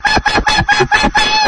描述：这是一个恐怖的女人尖叫。
声道立体声